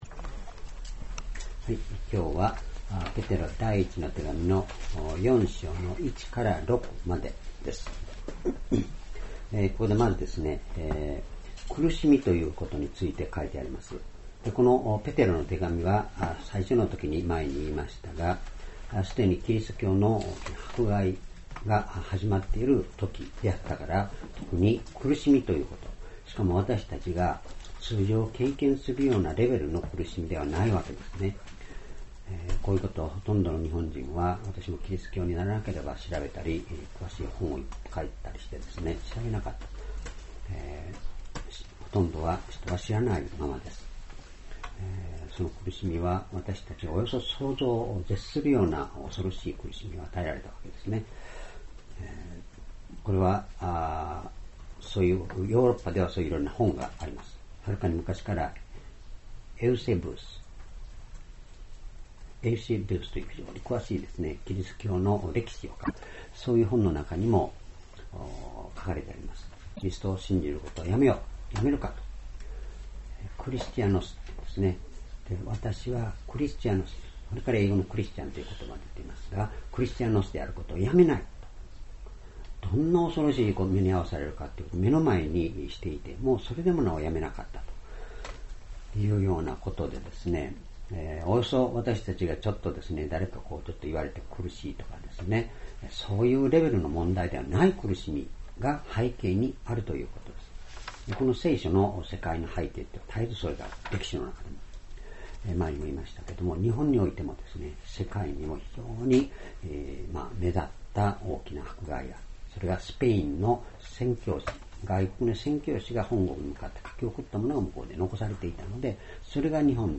主日礼拝日時 ２０１５年６月２８日 聖書講話箇所 Ⅰペテロ ４の１－６ 「苦しみの意味」 ※視聴できない場合は をクリックしてください。